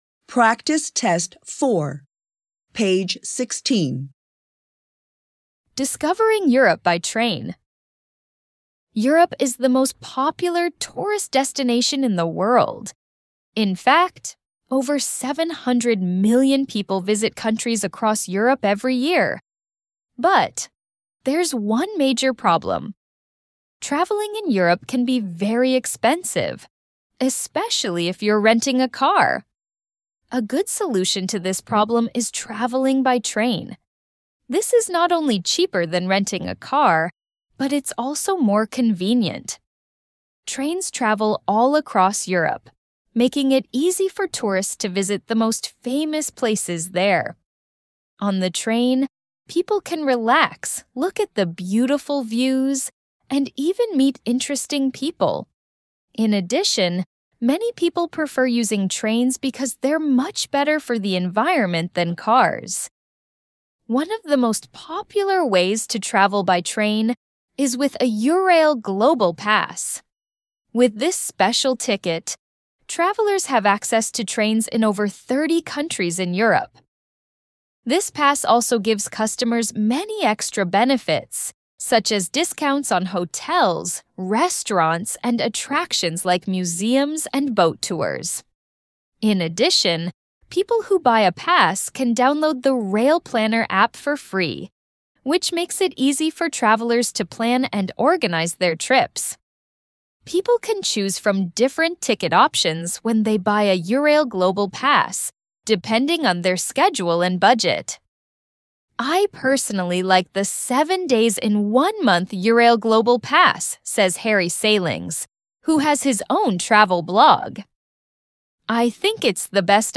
ECB Online offers you audio recordings of the reading texts from your coursebook to help you understand and enjoy your lessons.